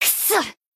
贡献 ） 分类:蔚蓝档案语音 协议:Copyright 您不可以覆盖此文件。
BA_V_Neru_Battle_Damage_1.ogg